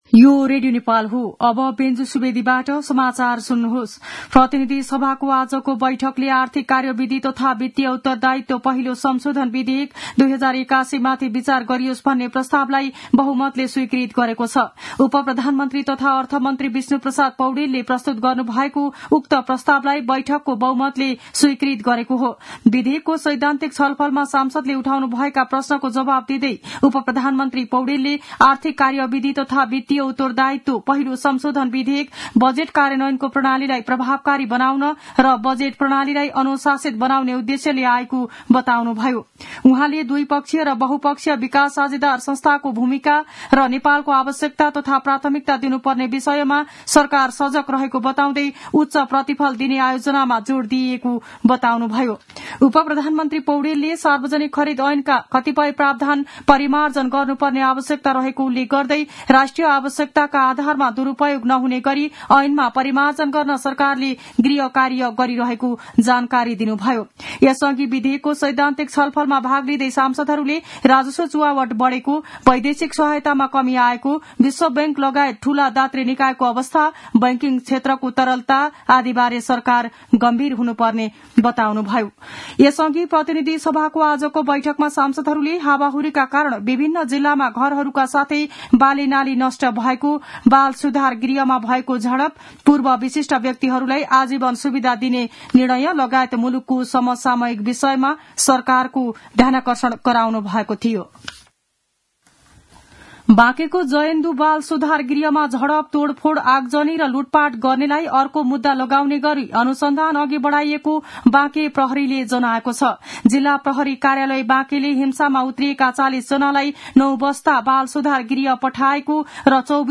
दिउँसो ४ बजेको नेपाली समाचार : ३ चैत , २०८१
4-pm-news-1-3.mp3